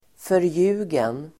Ladda ner uttalet
Uttal: [förj'u:gen]